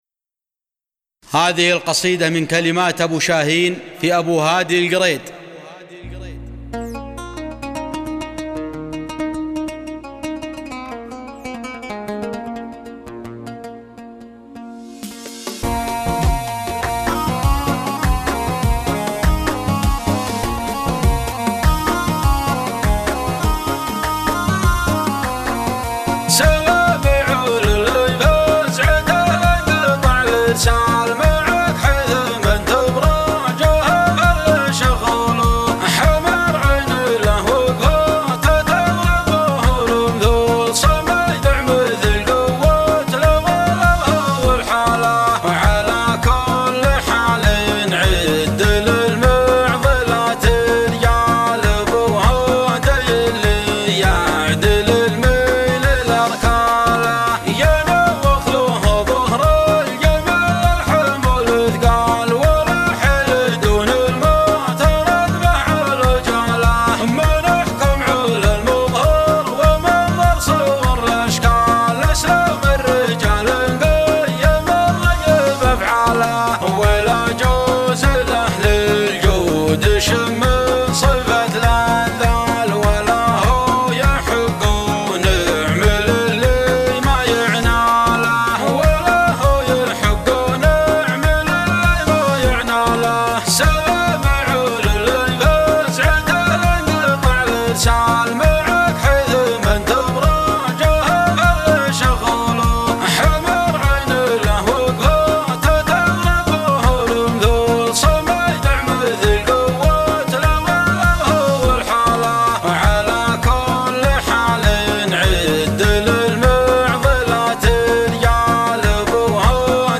القريد